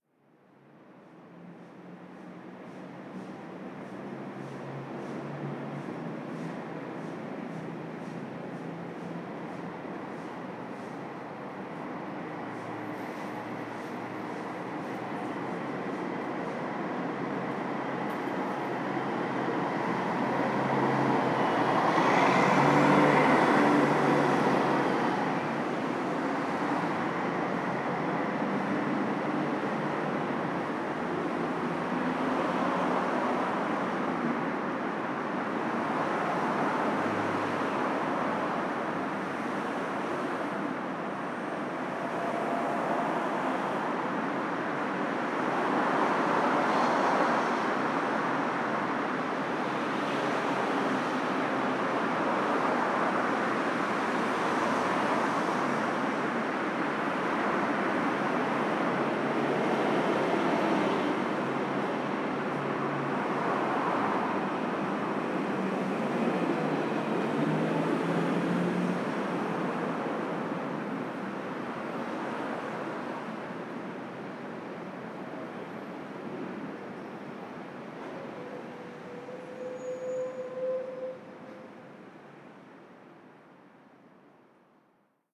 Ambiente de la Gran Vía, Madrid
Sonidos: Transportes Sonidos: Ciudad